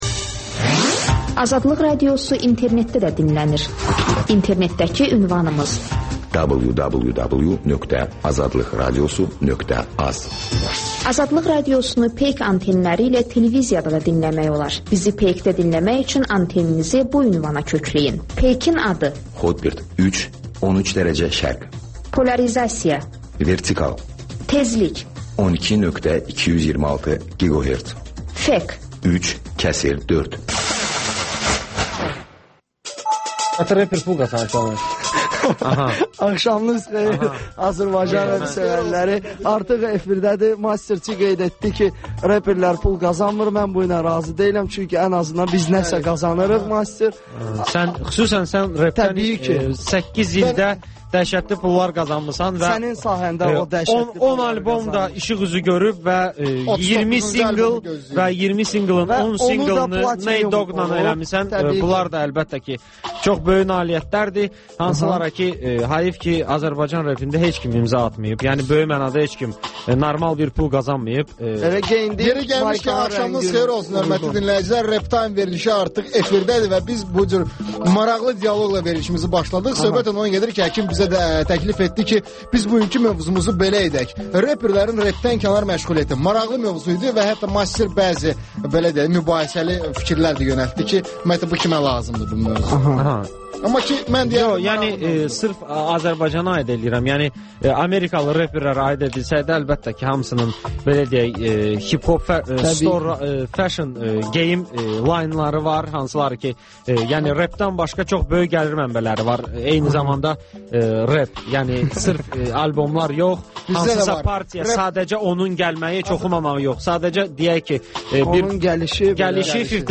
Gənclərin musiqi verilişi